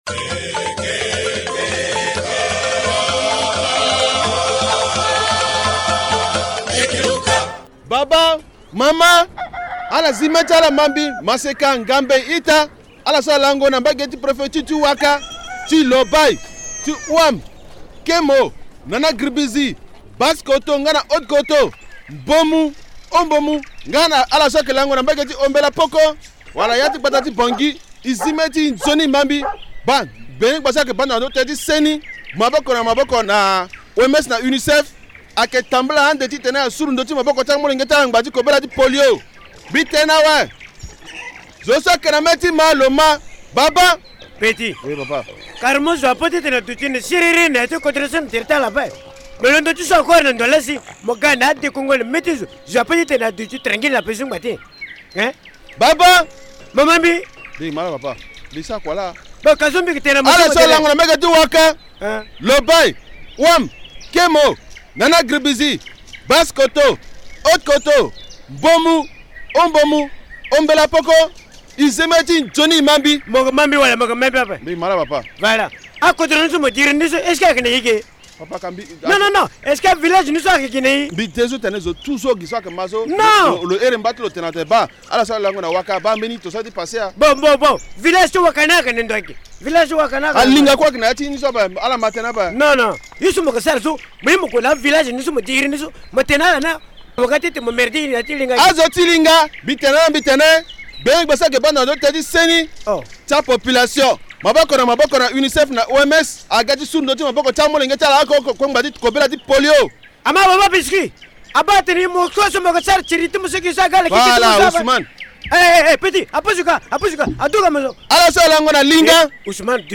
Selon les comédiens du Village Linga, depuis la découverte du virus de la poliomyélite dans certaines villes du pays, le Ministère de la Santé Publique, l’OMS et l’Unicef multiplient la vaccination. Une campagne cible les enfants de la Ouaka, la Lobaye, l’Ouham, Kémo, la Nana Gribizi, la Basse Kotto, la Haute Kotto, le Mbomou, le Haut Mbomou, l’Ombella-M’Poko et Bangui, la capitale.